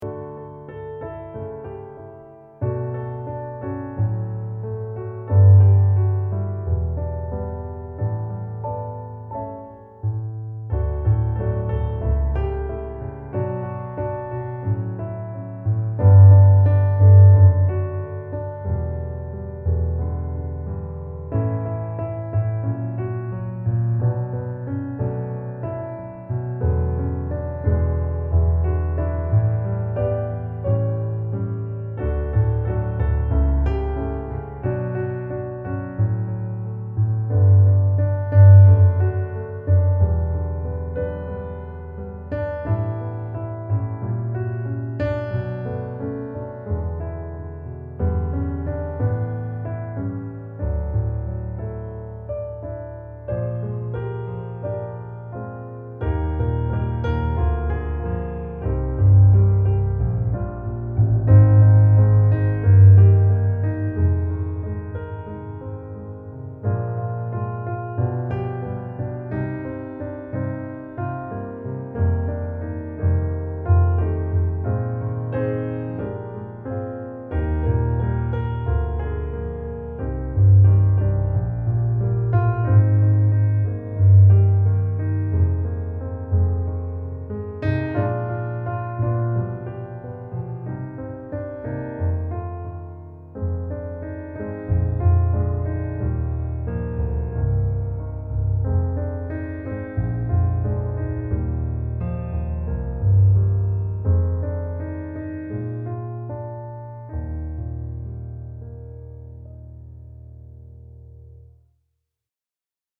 Att lära känna dig - musikbakgrund